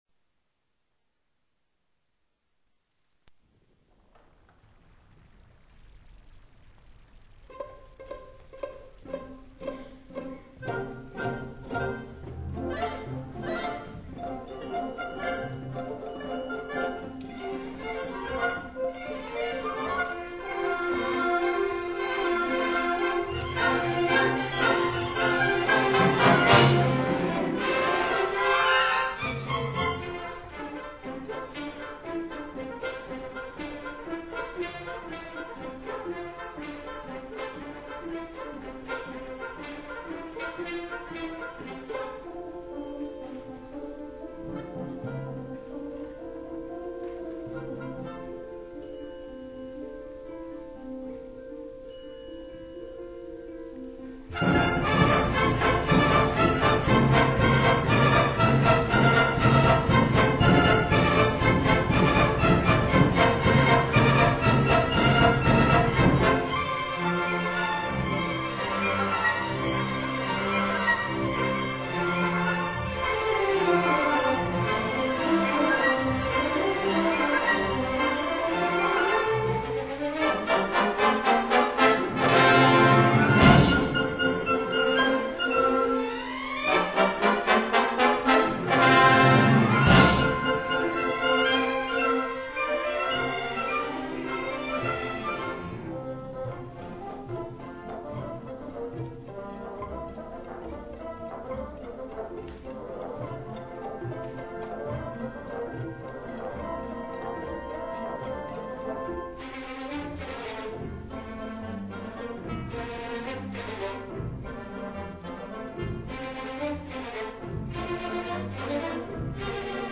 performed by Suburban Symphony Orchestra
España times for some of harp parts are: 48 - 54 seconds, 2:56 - 3:24, and briefly starting at 6:21